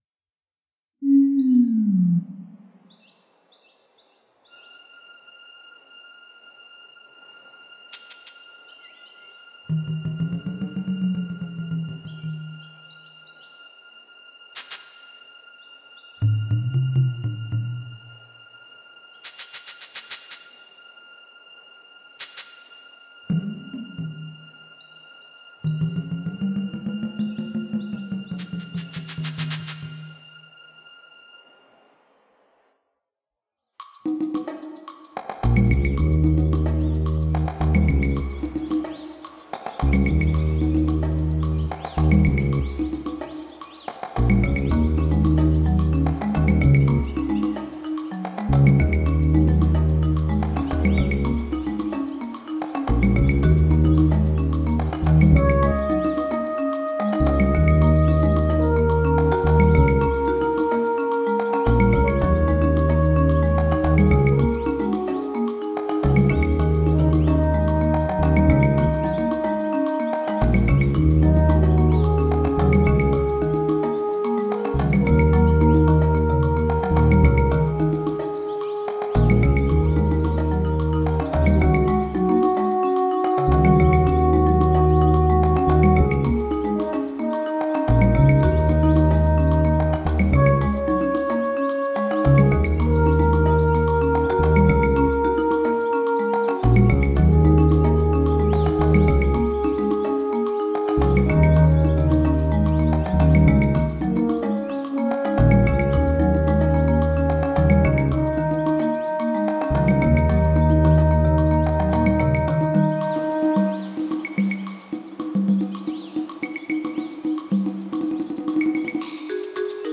Recorded digitally, using a M-Audio FireWire Audiophile.